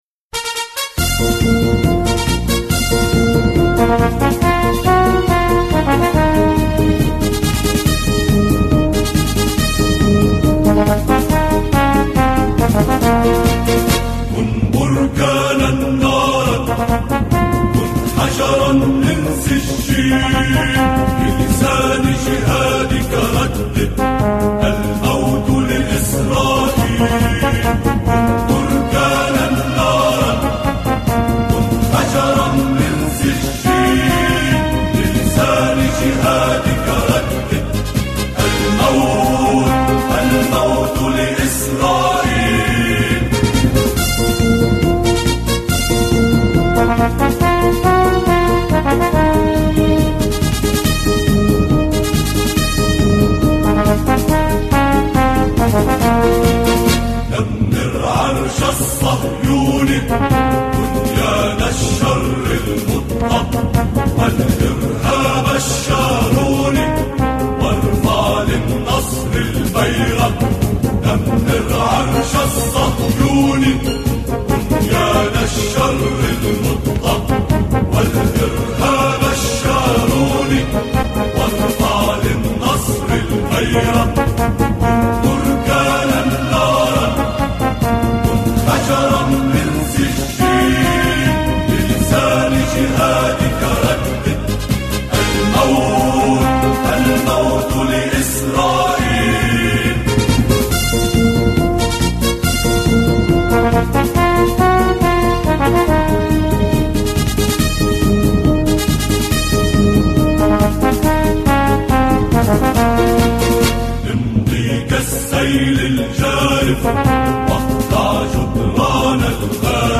أناشيد فلسطينية